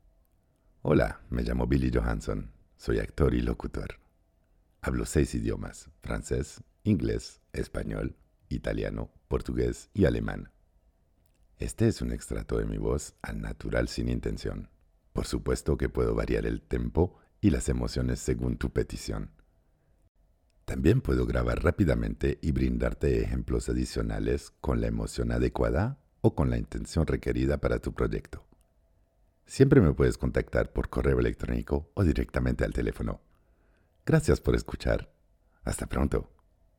Voix off
40 - 80 ans - Baryton-basse